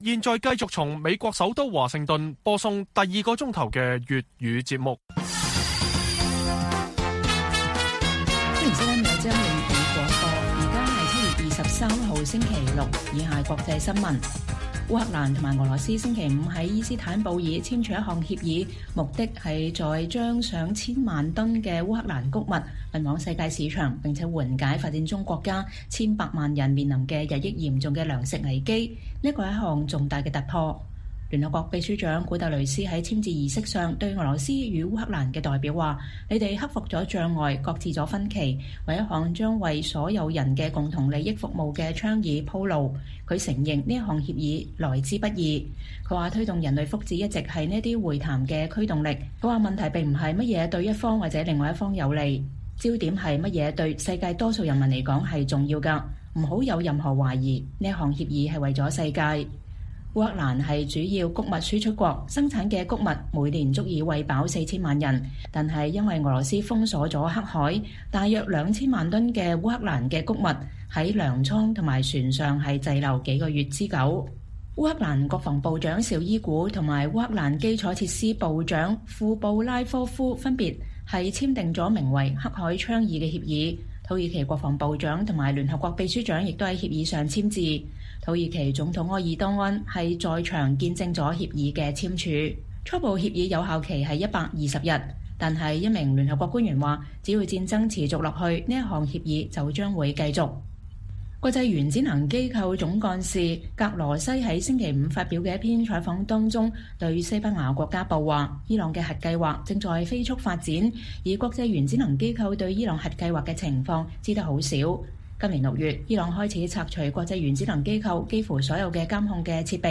粵語新聞 晚上10-11點：黑海倡議：烏克蘭與俄羅斯簽署的穀物協議主要內容及運作方式如何？